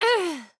Woman Die.ogg